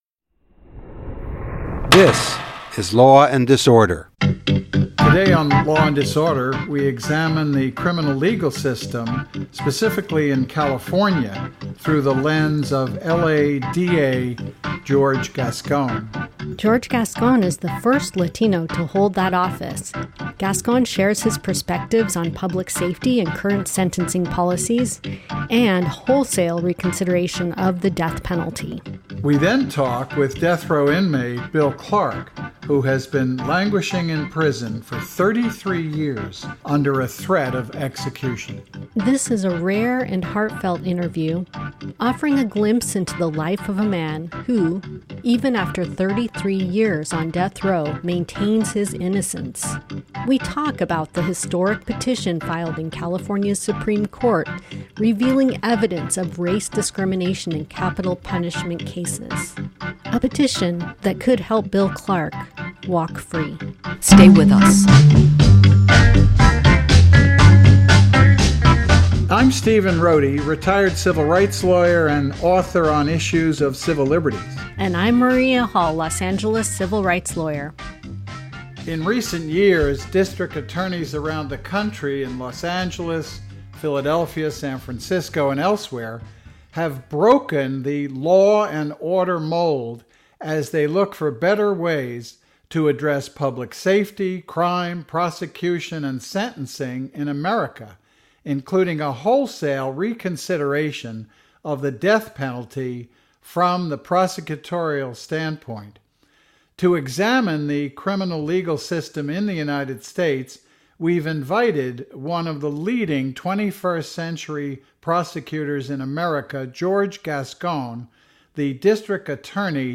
From The Flag To The Cross: Fascism American Style : Live Event